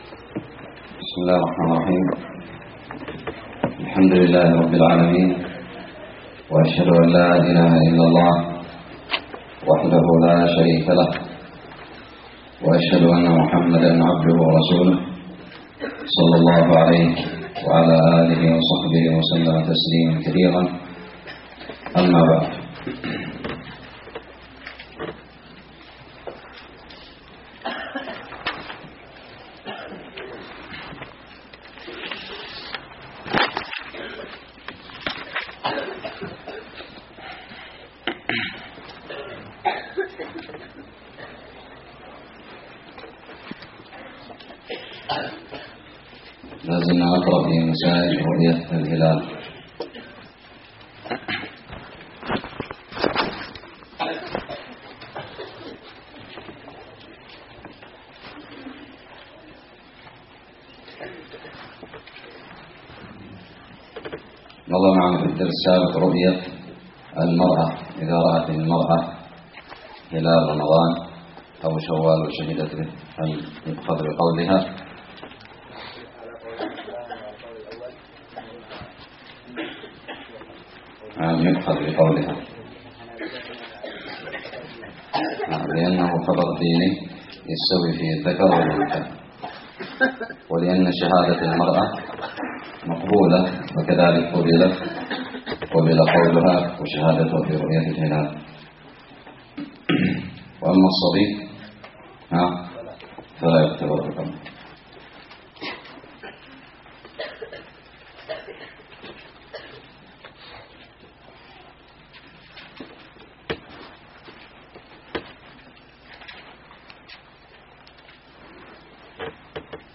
الدرس الخامس من كتاب الصيام من الدراري
ألقيت بدار الحديث السلفية للعلوم الشرعية بالضالع